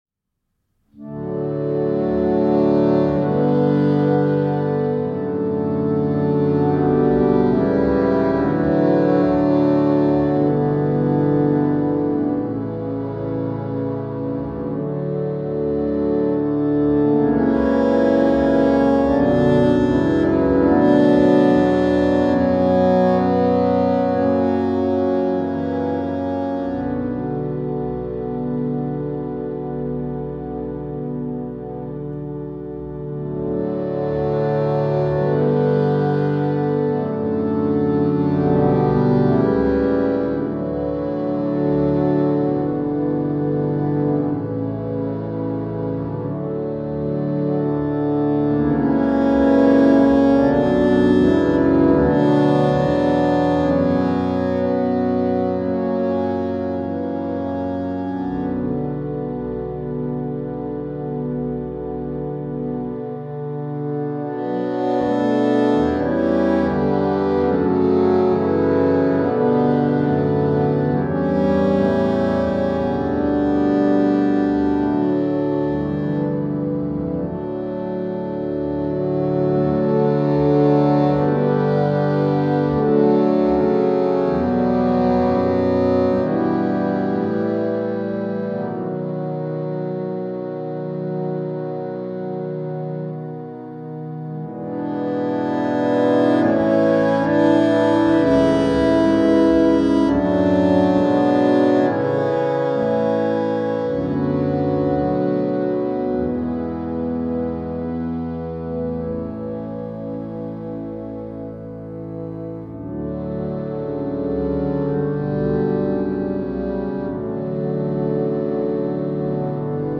Harmonium opnames
6. Hörügel
Koraalzetting J.S. Bach: O Haupt voll Blut und Wunden;